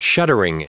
Prononciation du mot shuddering en anglais (fichier audio)
Prononciation du mot : shuddering